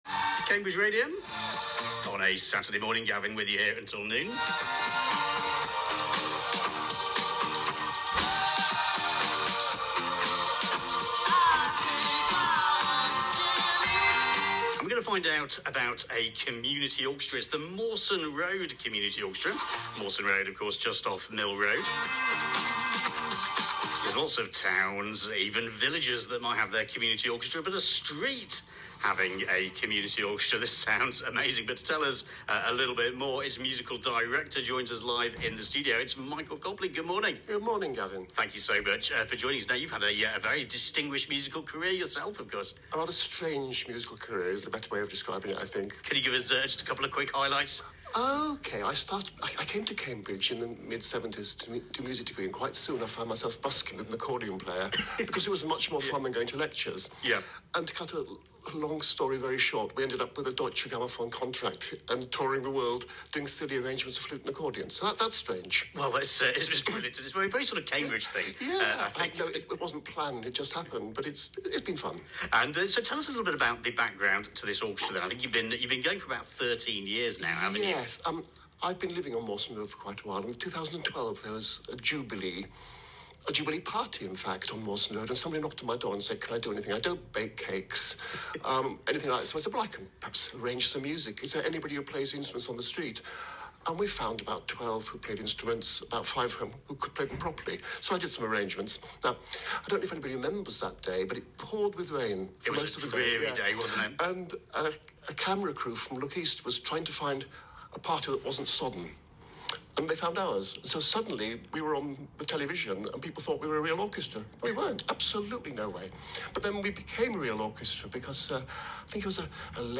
Cambridge Radio Interview